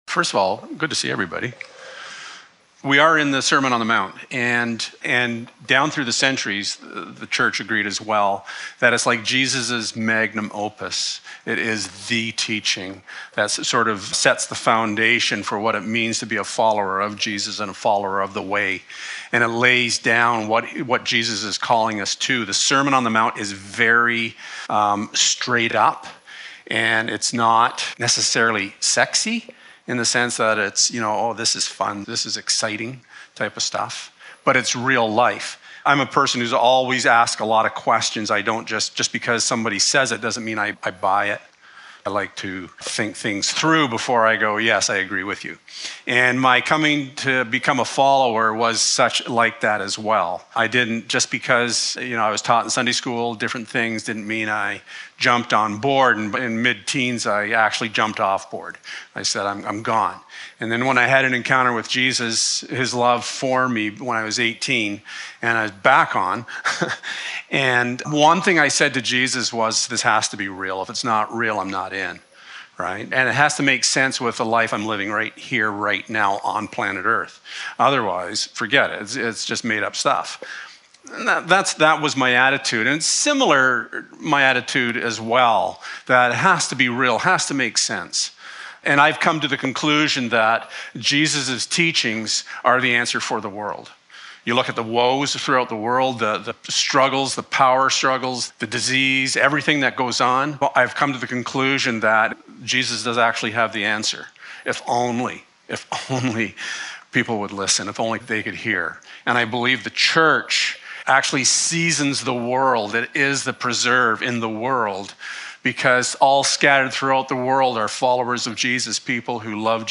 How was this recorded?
Free to Love Passage: Matthew 7: 7-11 Service Type: Sunday Morning Sometimes I think God loves a good wrestle.